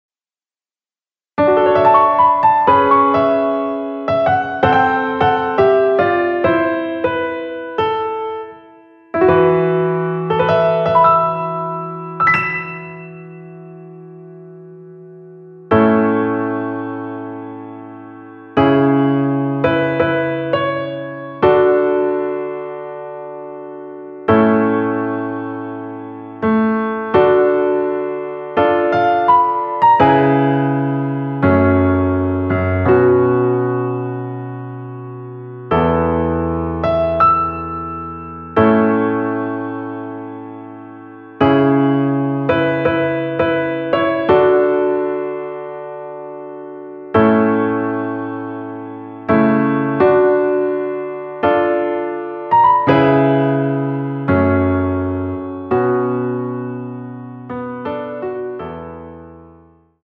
대부분의 여성분이 부르실수 있는 키로 제작 하였습니다.
앞부분30초, 뒷부분30초씩 편집해서 올려 드리고 있습니다.
중간에 음이 끈어지고 다시 나오는 이유는